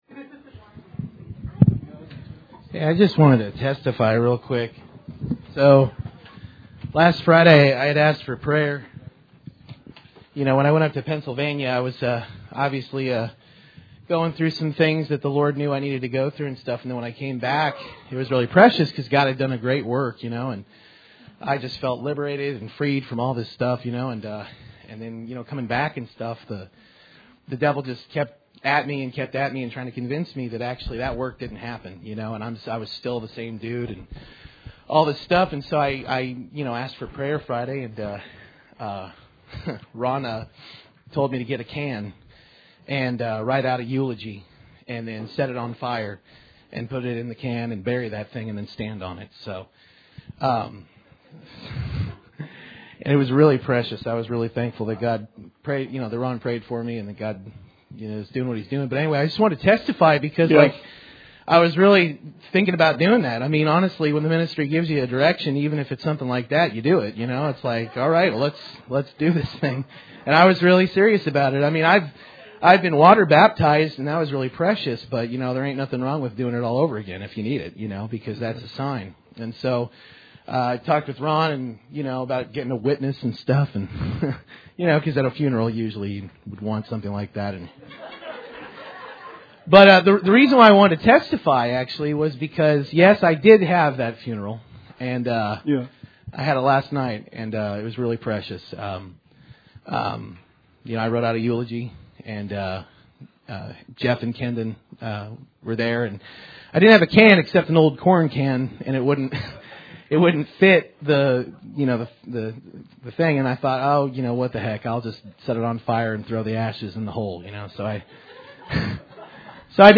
Bible Study 9/28/16